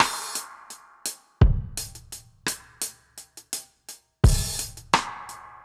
Index of /musicradar/dub-drums-samples/85bpm
Db_DrumsA_Wet_85_01.wav